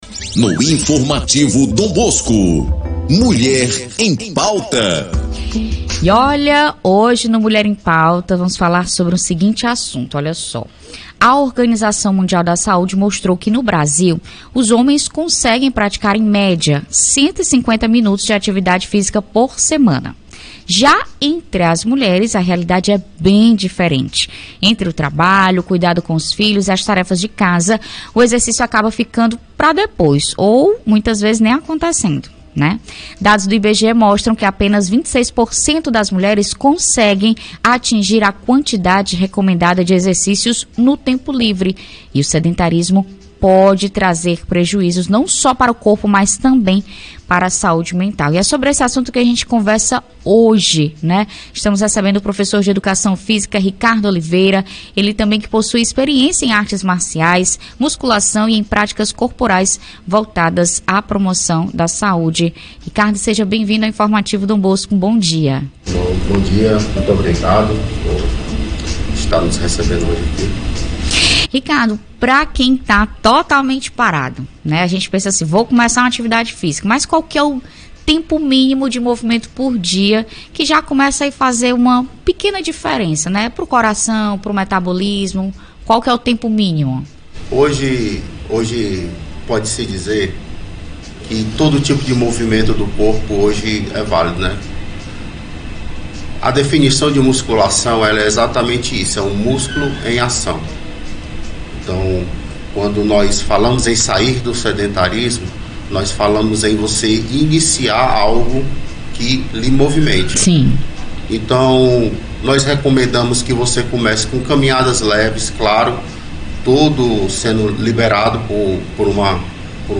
ENTREVISTA-2801.mp3